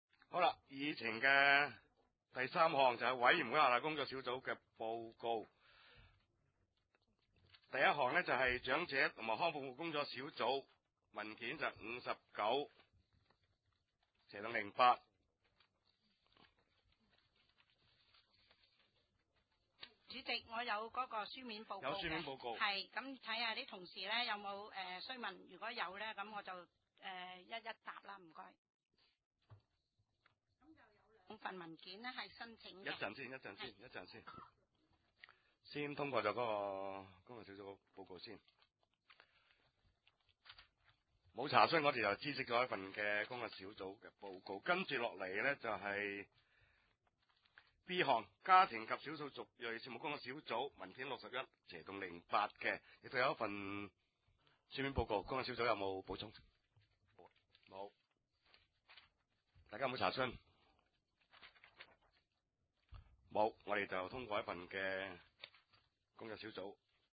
地點：深水埗區議會會議室